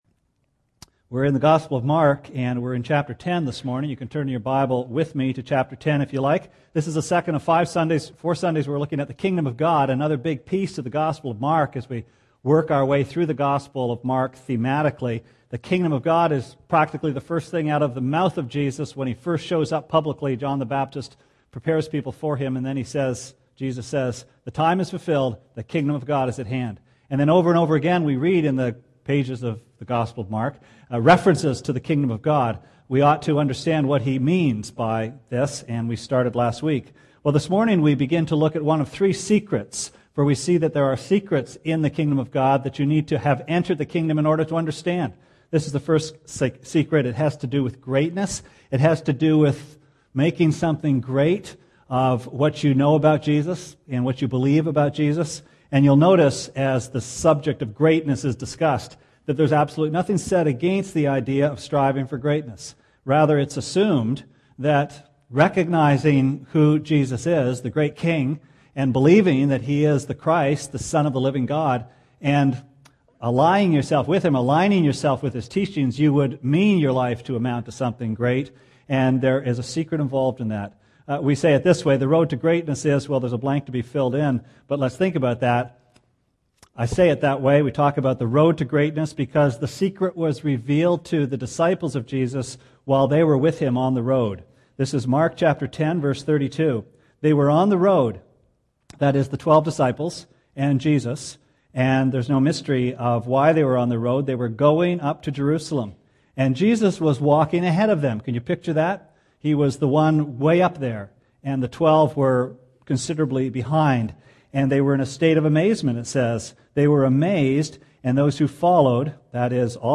Sermon Archives March 8, 2009: A Kingdom Secret #1 The second of four sermons from the Gospel of Mark on the kingdom of God.